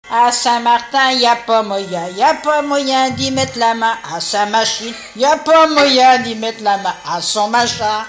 gestuel : à marcher
Pièce musicale inédite